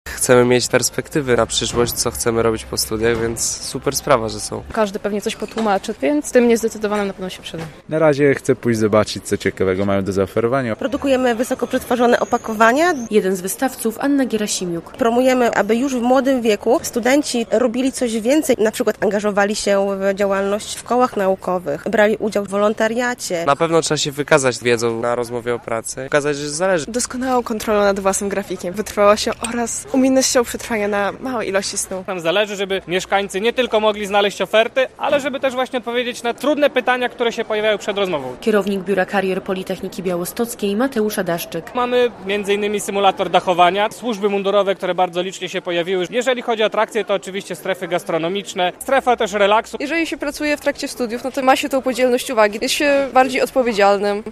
Radio Białystok | Wiadomości | Wiadomości - Pracodawcy wystawiają się na politechnice